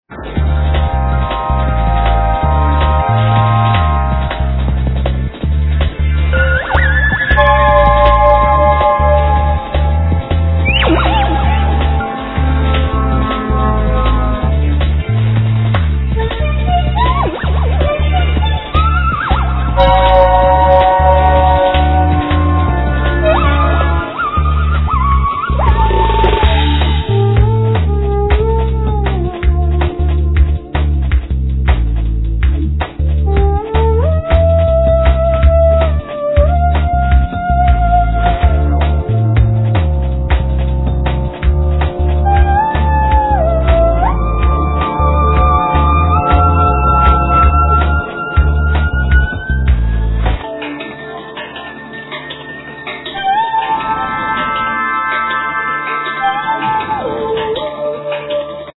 Theremin, Midi-theremein, Sampling, Bass
Flute, Alt & Bass Flute, Clarinet, Bass clalinet
Violin samples
Double bass
Vocals
Guitar
Drums, Keyboards